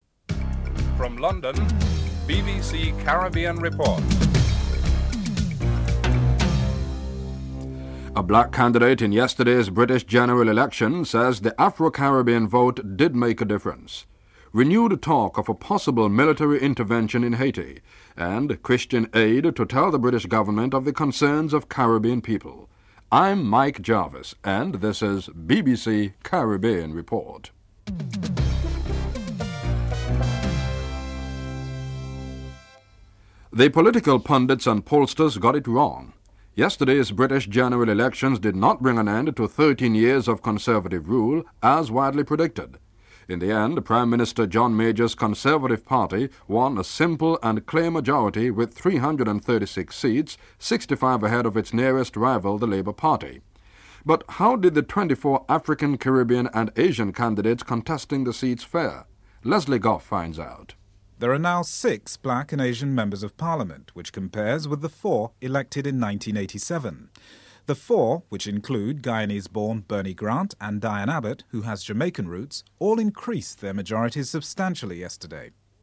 1. Headlines (00:00-00:32)